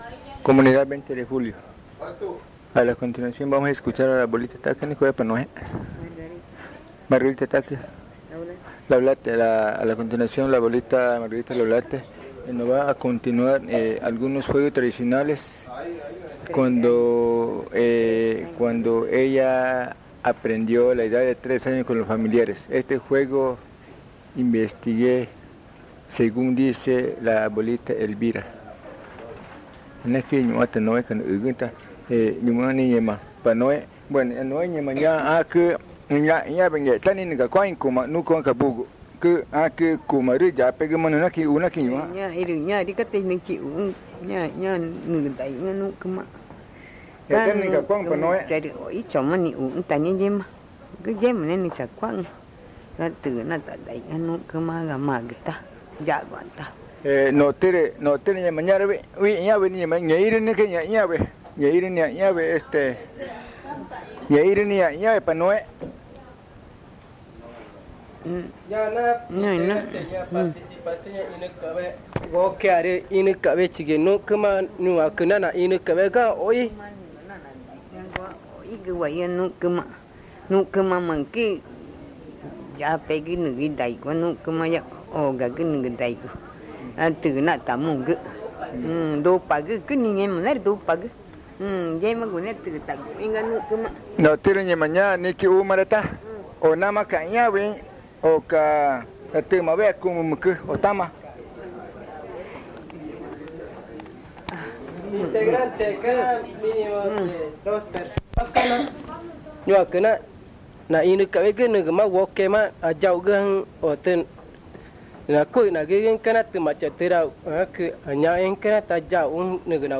San Juan del Socó, río Loretoyacu, Amazonas (Colombia)
Al finalizar la explicación del juego, la abuela entona un canto sobre la flor de tangarana (konüwachakü).
At the end of the explanation of the game, the elder sings a chant about the tangarana flower (konüwachakü).